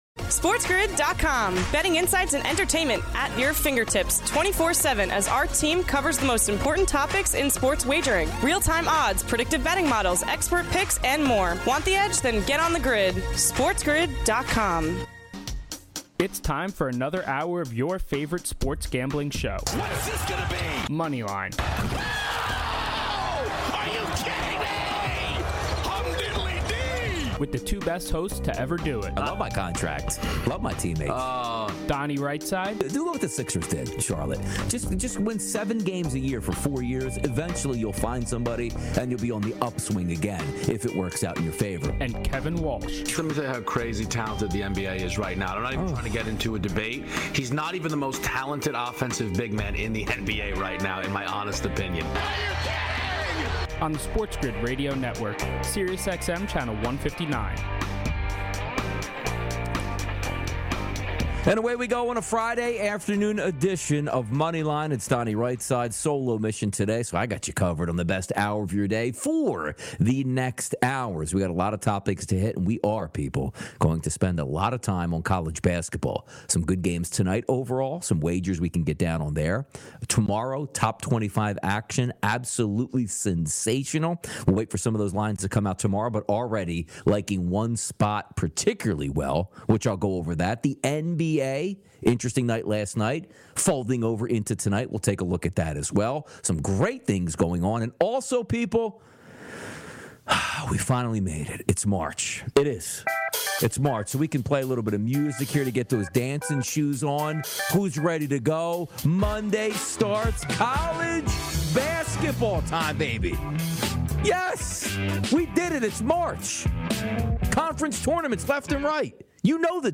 on a solo mission opens show talking college basketball heading into March Madness.